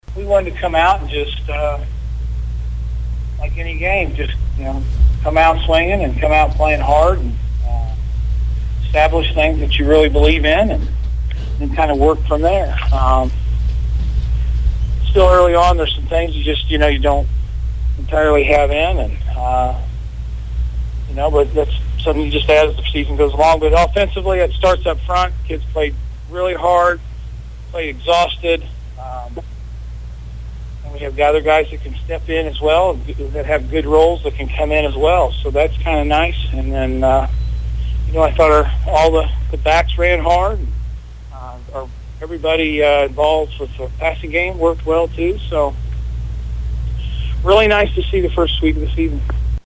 broadcast on KTTN FM 92.3